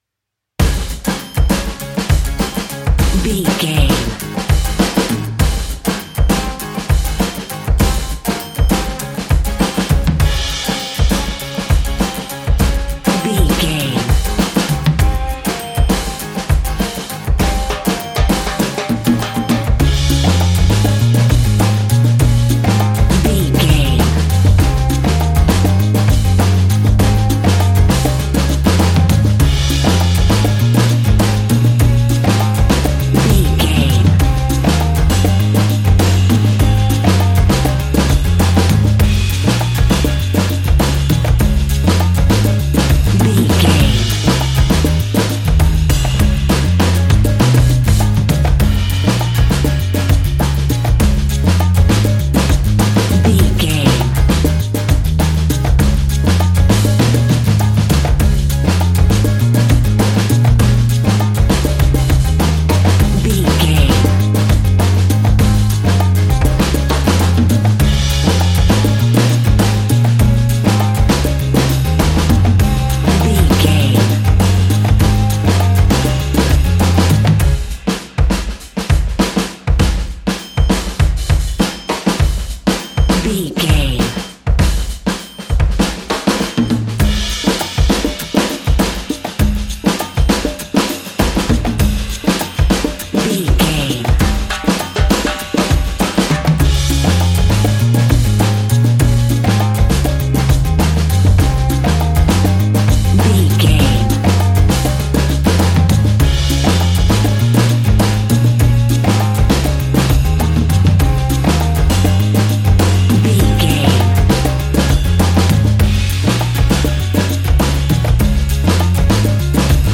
Ionian/Major
D♭
calypso
steelpan
worldbeat
drums
percussion
bass
brass
guitar